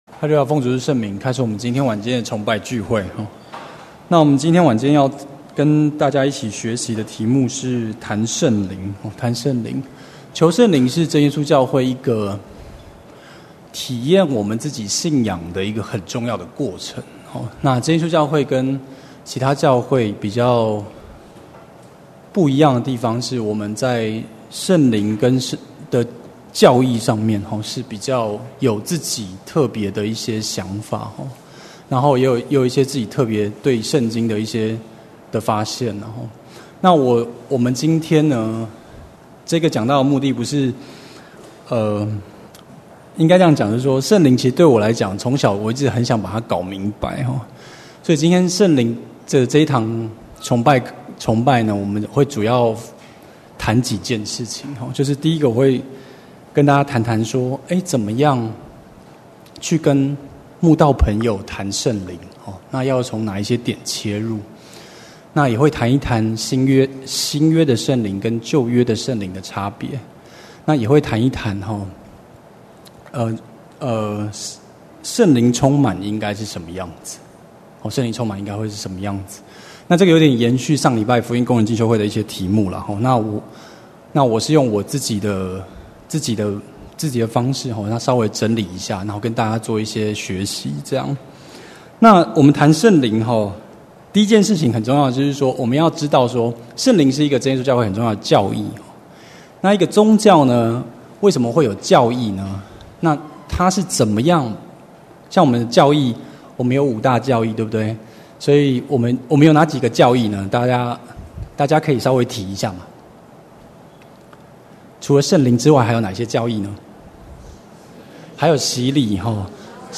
2017年7月份講道錄音已全部上線